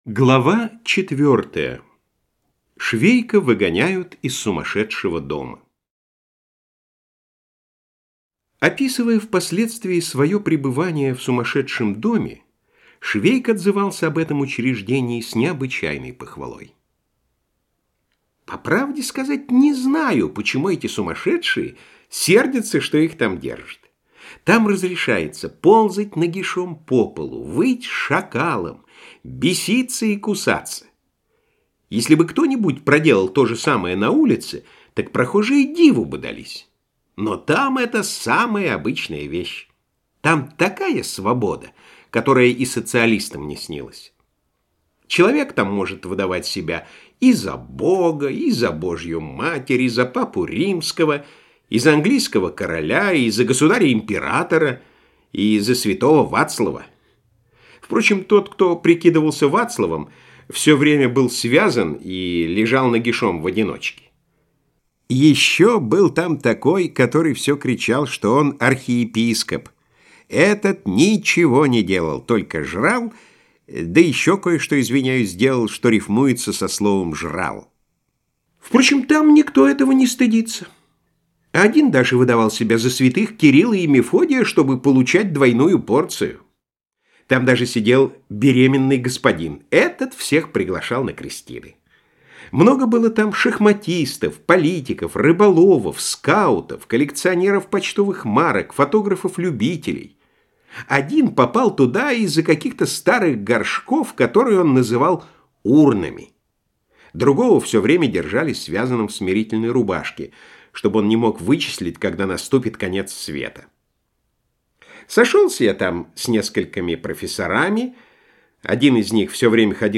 Аудиокнига Похождения бравого солдата Швейка - купить, скачать и слушать онлайн | КнигоПоиск